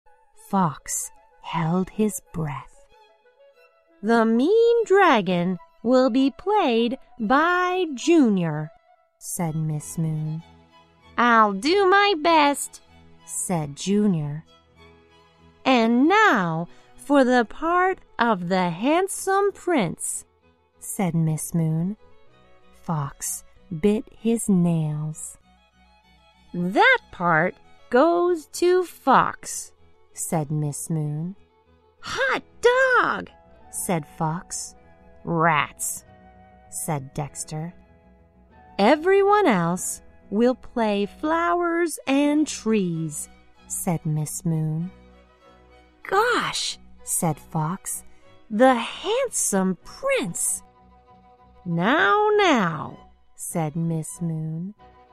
在线英语听力室小狐外传 第30期:朱尼尔的听力文件下载,《小狐外传》是双语有声读物下面的子栏目，非常适合英语学习爱好者进行细心品读。故事内容讲述了一个小男生在学校、家庭里的各种角色转换以及生活中的趣事。